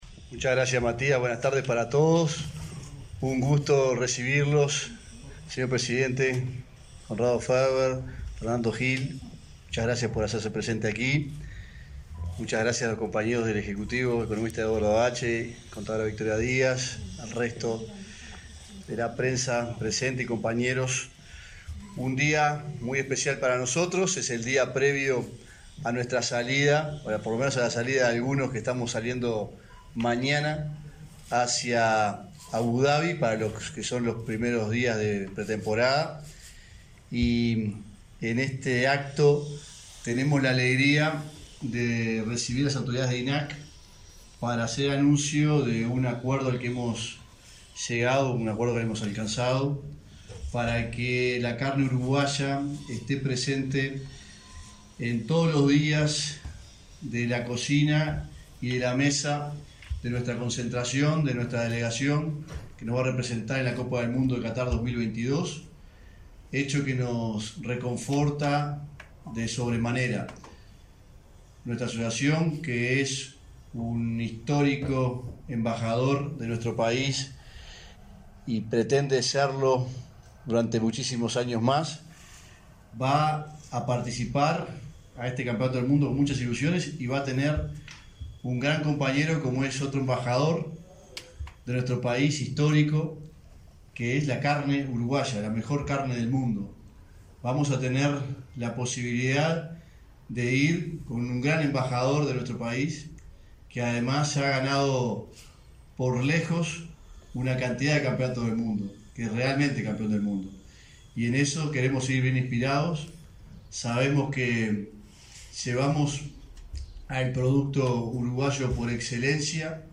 Conferencia de prensa por la firma de convenio entre INAC y AUF